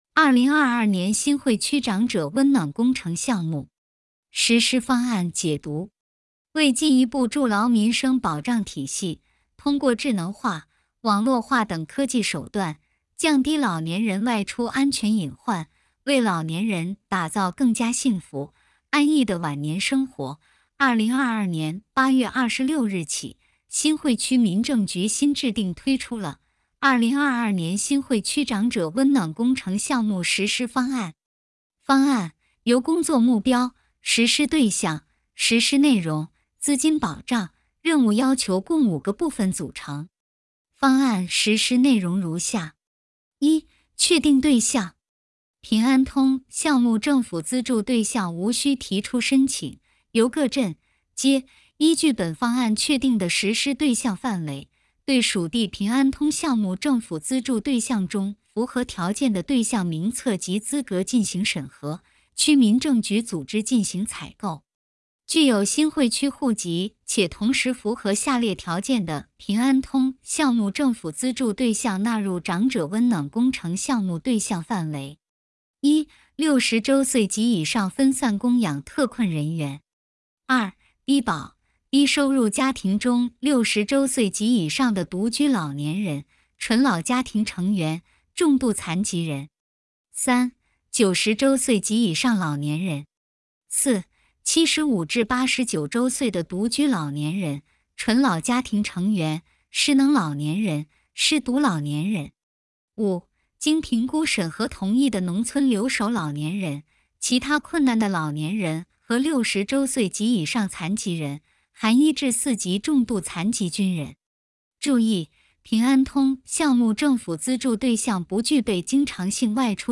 《2022年新会区“长者温暖工程”项目实施方案》音频解读（语速偏慢）(1).mp3